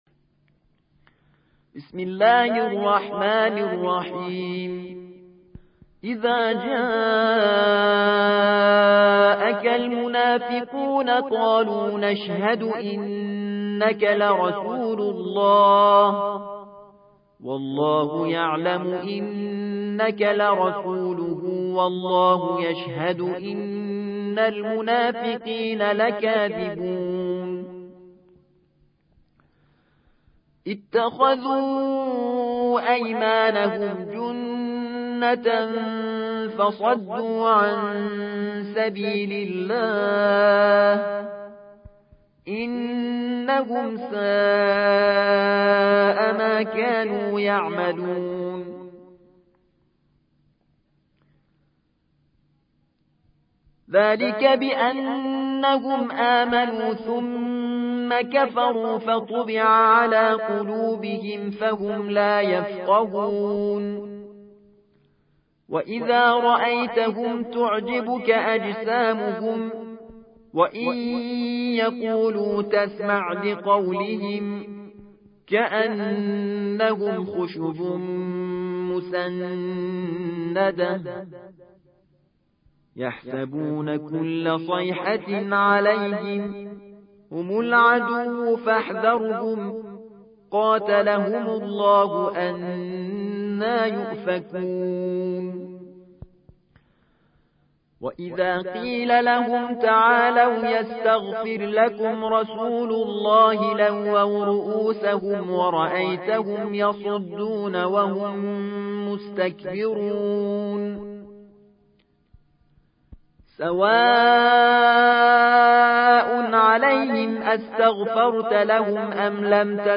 63. سورة المنافقون / القارئ